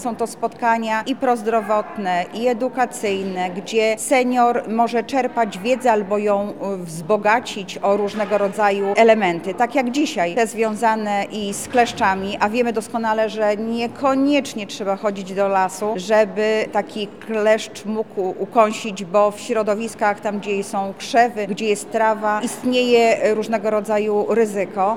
W Lubelskim Urzędzie Wojewódzkim odbył się Dzień Otwarty Inspekcji Sanitarnej.
Anna Augustyniak  – mówi Anna Augustyniak, Zastępca Prezydenta Miasta Lublin ds. Społecznych.